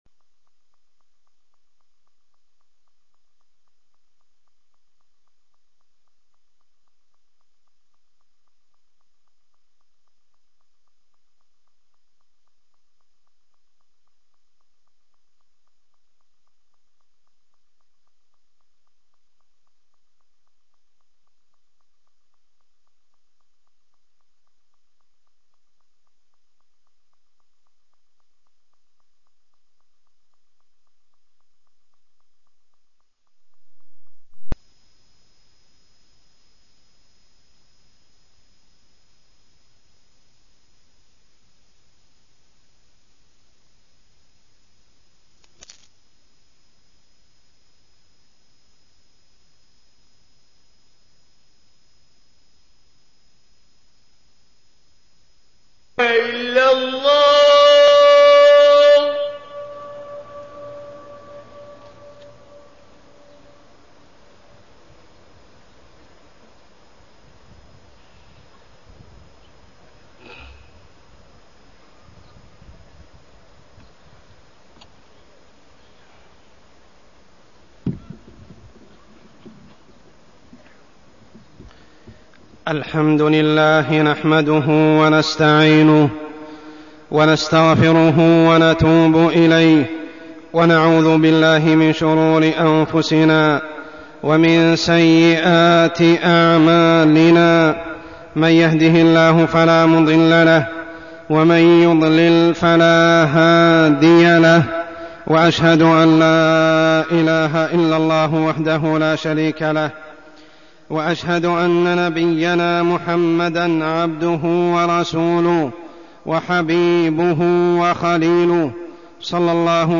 تاريخ النشر ١٩ شعبان ١٤١٨ هـ المكان: المسجد الحرام الشيخ: عمر السبيل عمر السبيل صلة الرحم